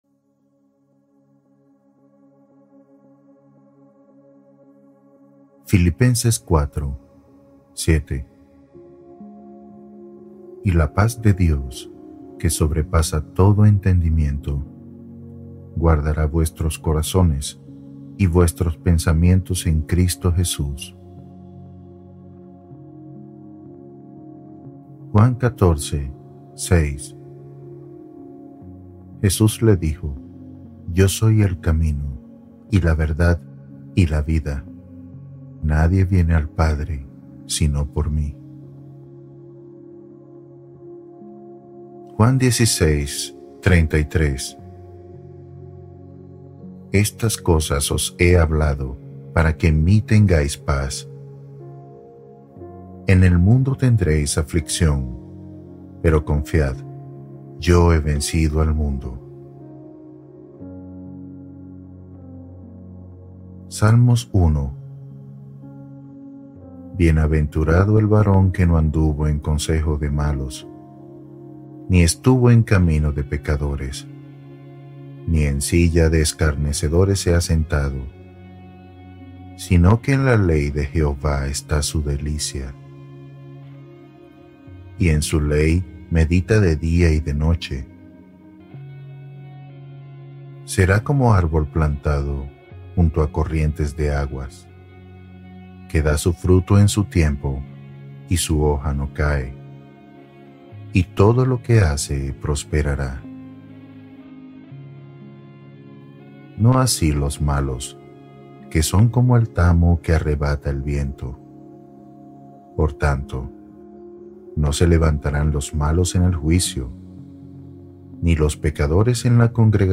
Promesas de Dios para dormir tranquilo | Biblia hablada 1960